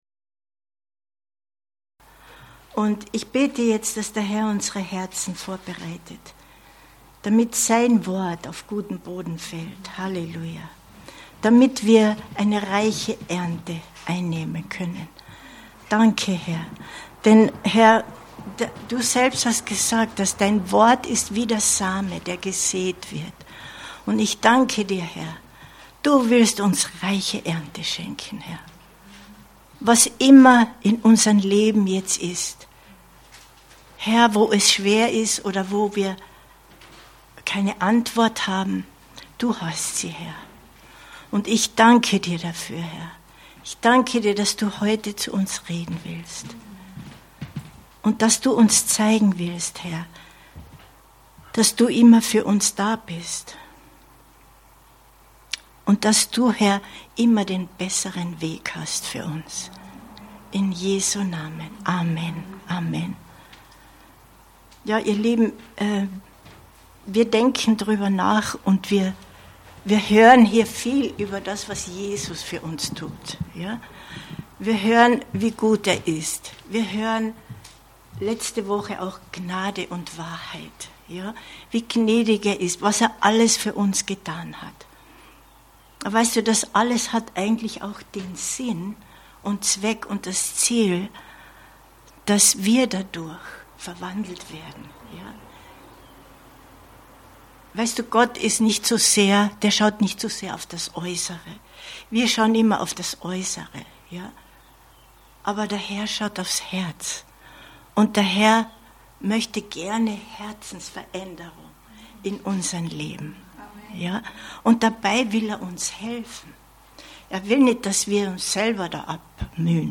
Lebe dein Leben zur Ehre des Names Jesu 23.07.2023 Predigt herunterladen